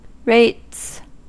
Ääntäminen
Synonyymit (brittienglanti) property tax Ääntäminen US Tuntematon aksentti: IPA : /ɹeɪts/ Haettu sana löytyi näillä lähdekielillä: englanti Käännös 1. hinnasto Rates on sanan rate monikko.